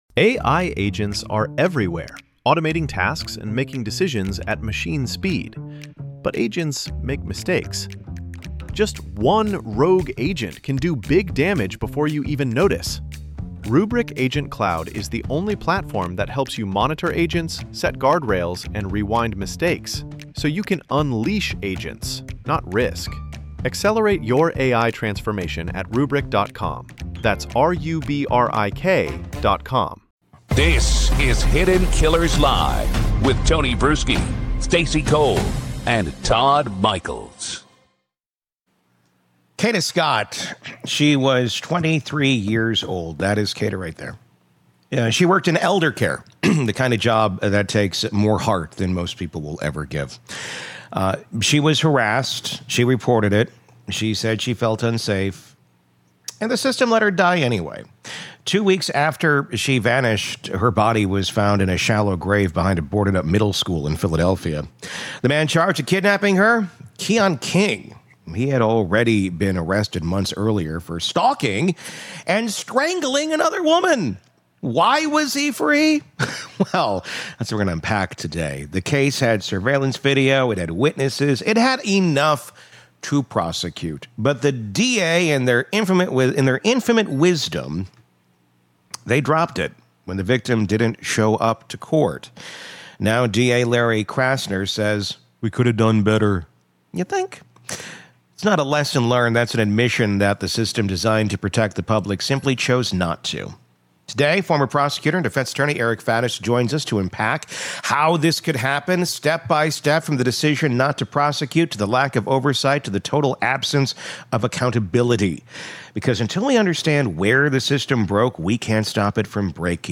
In this explosive interview